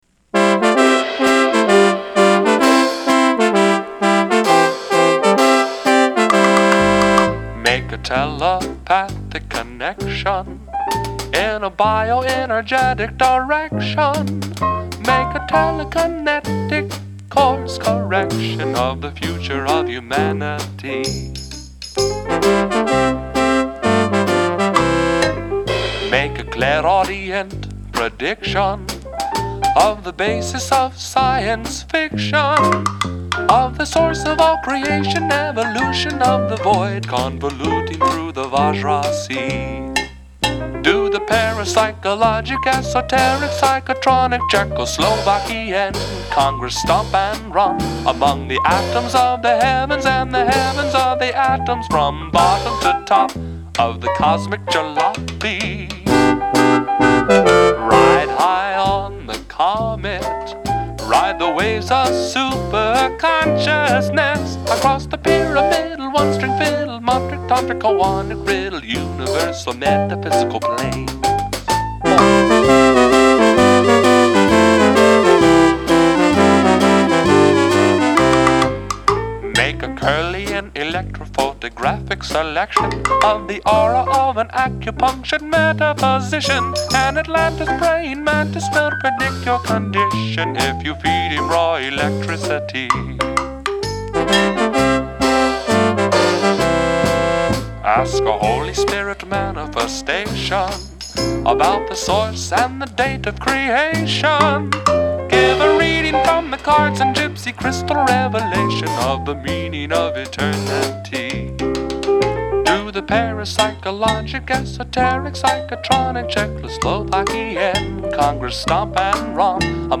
ARP Synthesizer